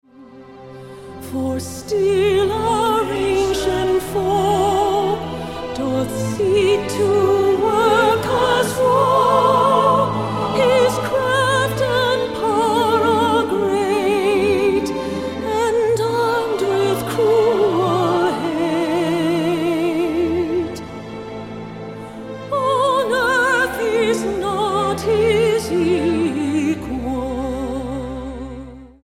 STYLE: MOR / Soft Pop
With choral and orchestral accompaniment, it's a lushly produced set, and, to those rather unfamiliar with the current direction of this well known figure, a perfect introduction.